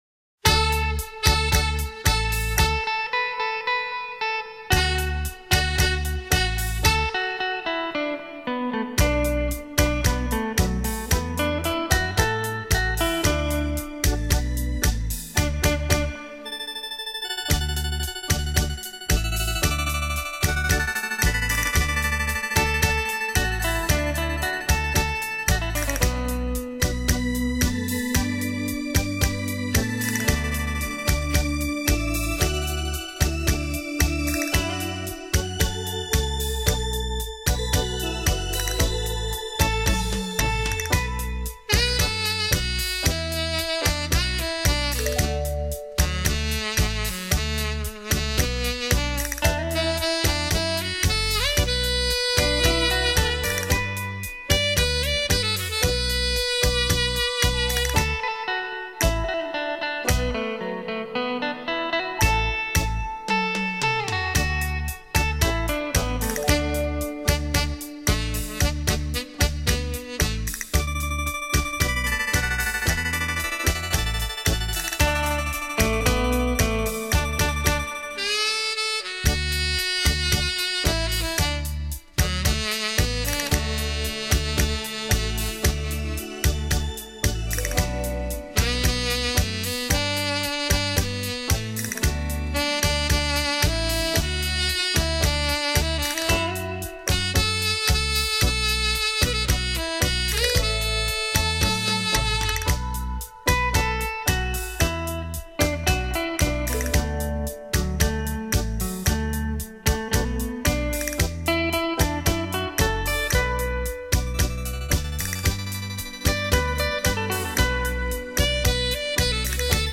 其创作的作品及编曲　旋律优美　节奏动听